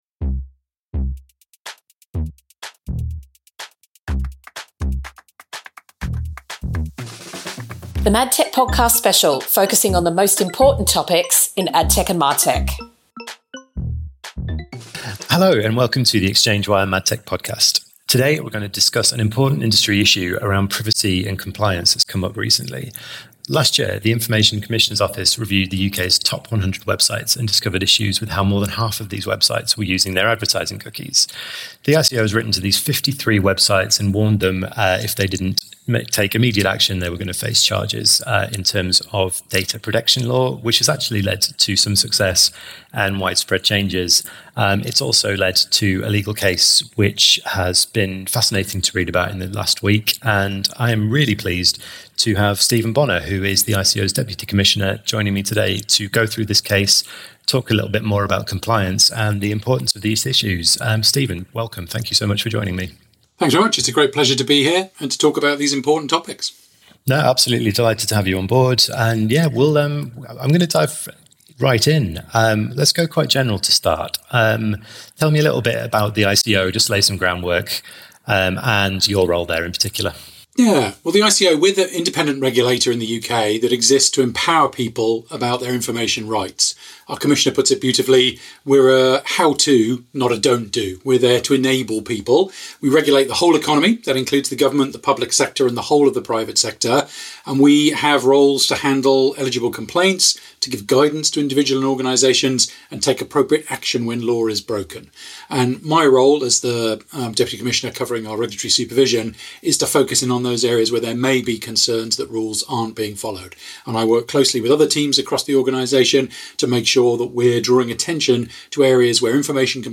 is joined by the Information Commissioner's Office's deputy commissioner to discuss the ICO's recent study around the data compliance of the UK's top 100 websites